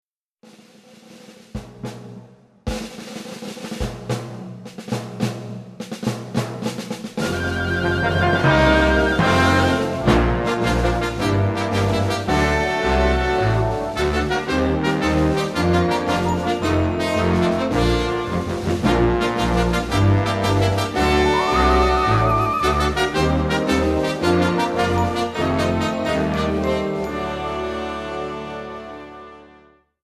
インストゥルメンタル